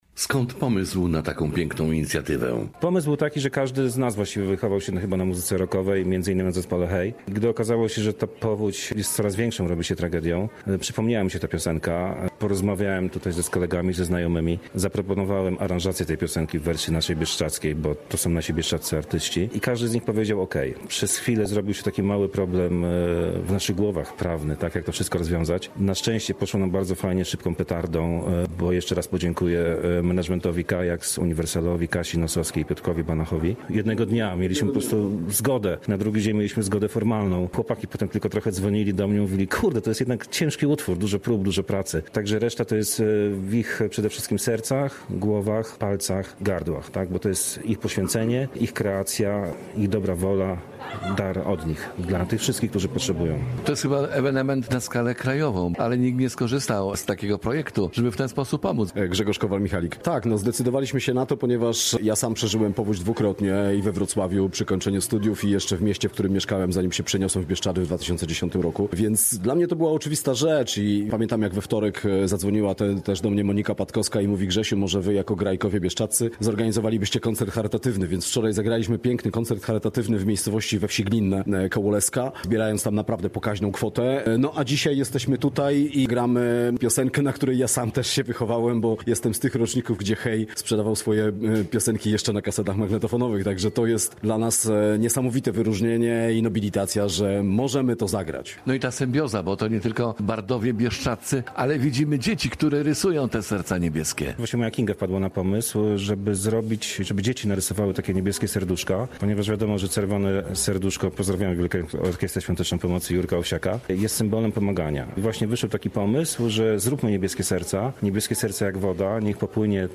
W nowej wersji na gitarach zagrali
na akordeonie
Cover został nagrany w Bazie pod Otrytem w Polanie